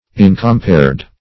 \In`com*pared"\